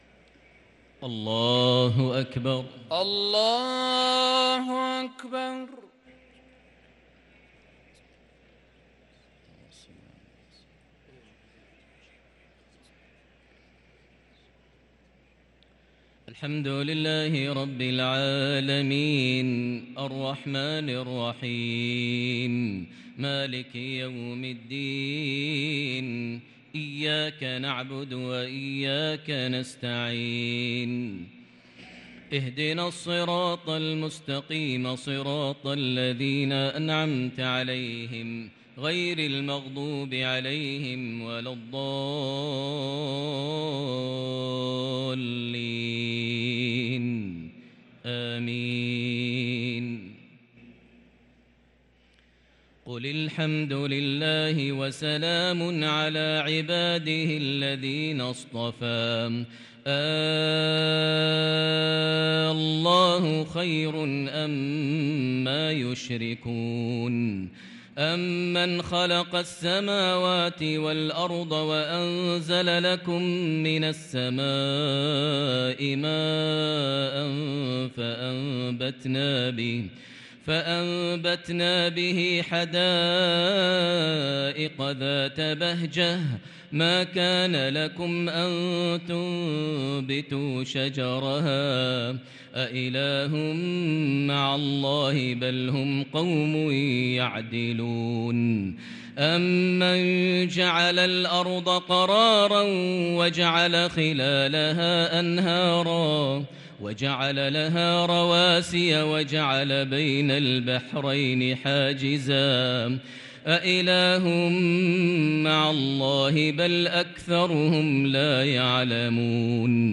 صلاة التراويح ليلة 23 رمضان 1443 للقارئ ماهر المعيقلي - الاربع التسليمات الاولى صلاة التهجد